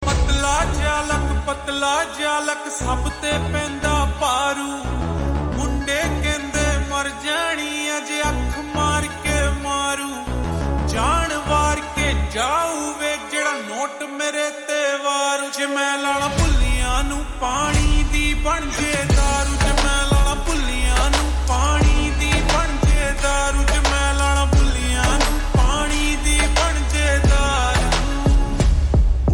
Punjabi Songs
Slow Reverb Version
• Simple and Lofi sound
• Crisp and clear sound